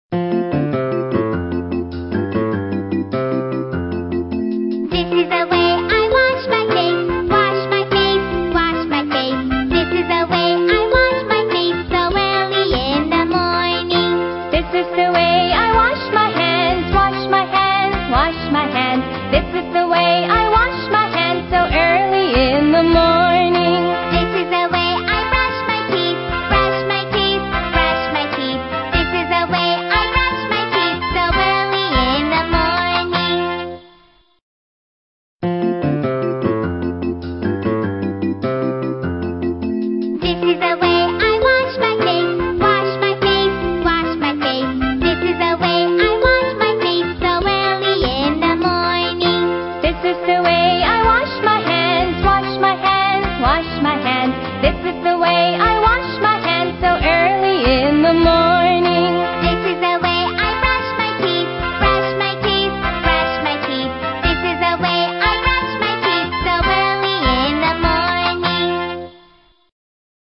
在线英语听力室英语儿歌274首 第230期:This Is the Way(2)的听力文件下载,收录了274首发音地道纯正，音乐节奏活泼动人的英文儿歌，从小培养对英语的爱好，为以后萌娃学习更多的英语知识，打下坚实的基础。